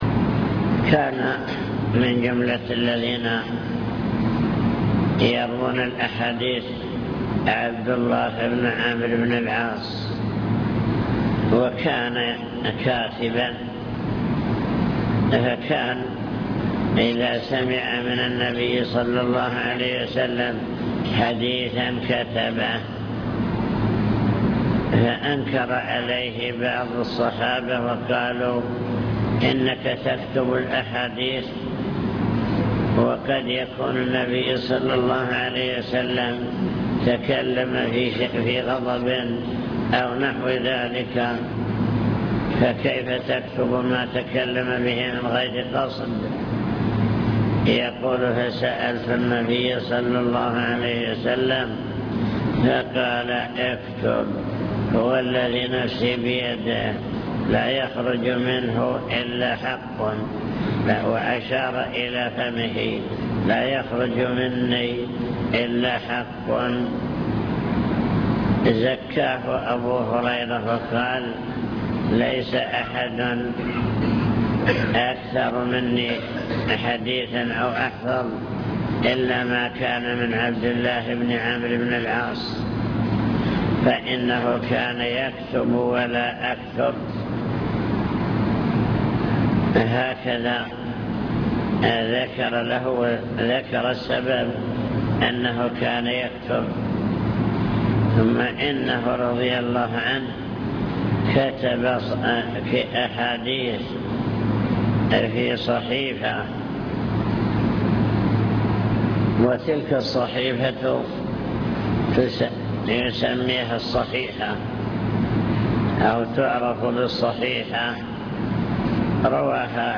المكتبة الصوتية  تسجيلات - محاضرات ودروس  محاضرات بعنوان: عناية السلف بالحديث الشريف عناية الصحابة بالحديث